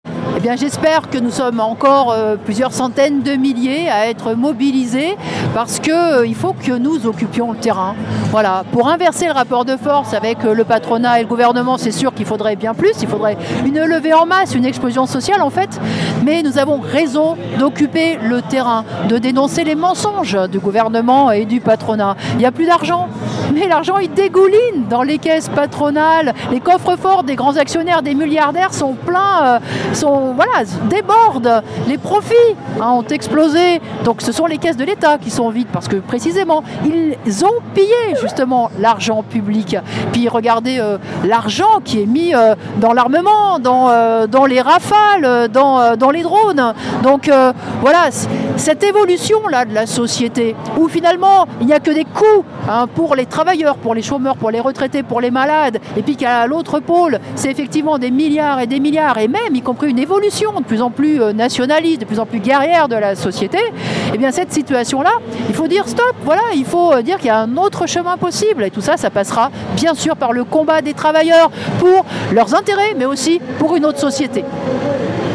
Nathalie Arthaud à la manifestation du 2 octobre